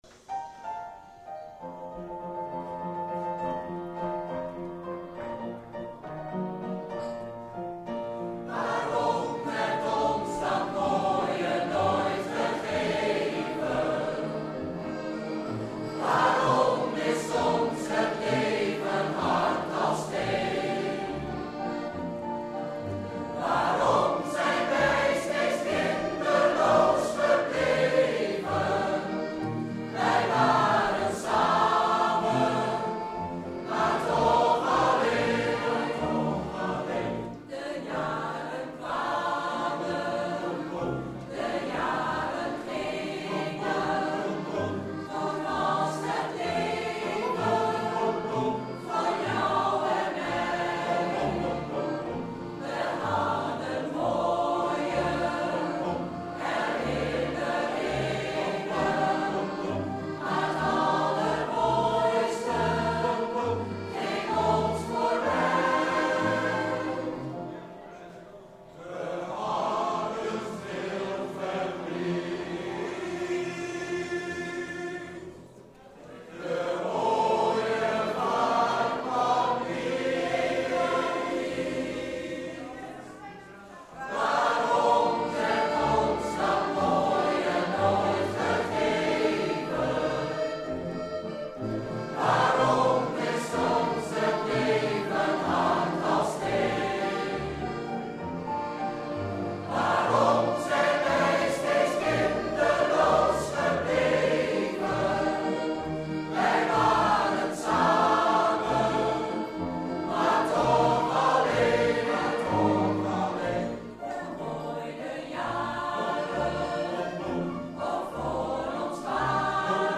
De (technische) kwaliteit loopt nogal uiteen.